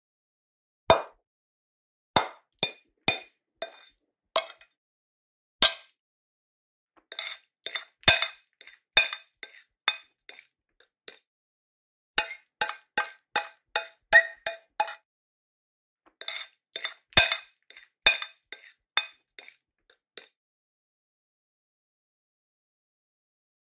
Âm thanh khi ăn Món Tây bằng Dao Nĩa trên đĩa
Thể loại: Tiếng ăn uống
Âm thanh leng keng, lách cách của dao và nĩa chạm vào đĩa sứ, kết hợp tiếng cắt, kéo và gõ nhẹ, tạo không khí đặc trưng của bữa ăn phong cách Âu.... Tái hiện trọn vẹn trải nghiệm thưởng thức ẩm thực phương Tây, từ tiếng dao nĩa va chạm, sột soạt khi cắt miếng thịt, đến âm vang nhẹ của bữa tiệc sang trọng.
am-thanh-khi-an-mon-tay-bang-dao-nia-tren-dia-www_tiengdong_com.mp3